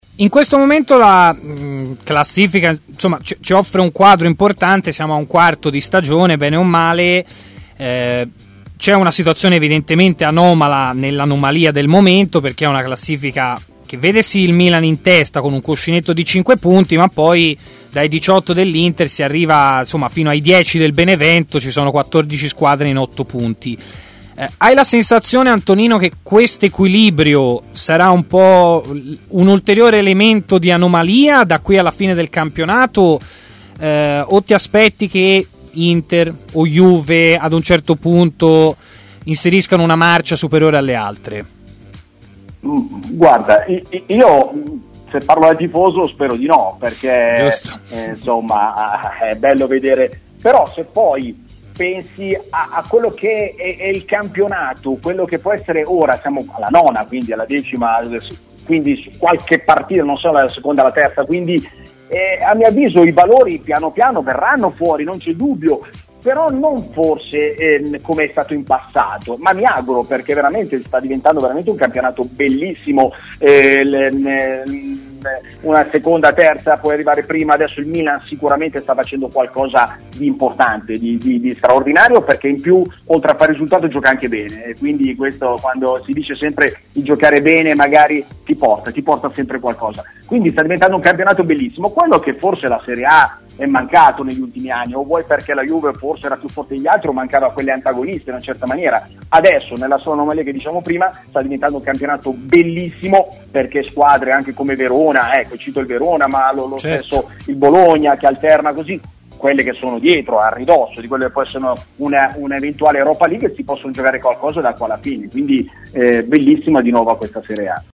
si è collegato in diretta con Stadio Aperto, trasmissione di TMW Radio